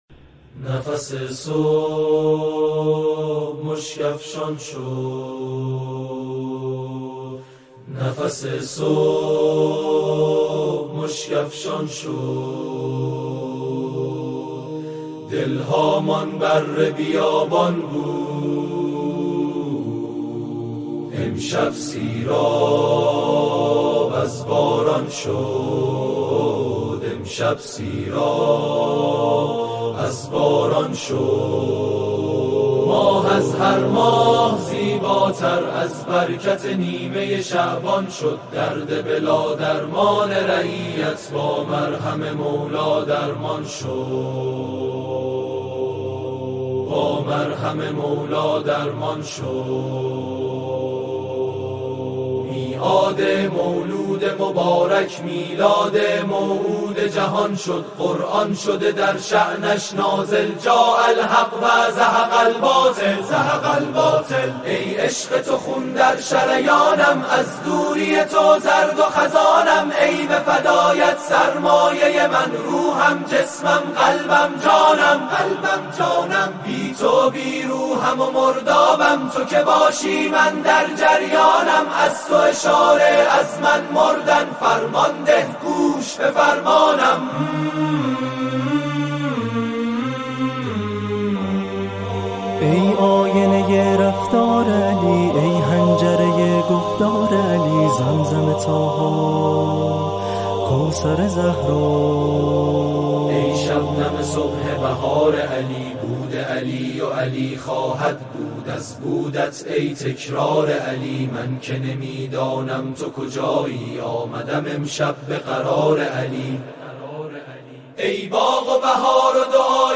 نماهنگ سرود
به صورت آکاپلا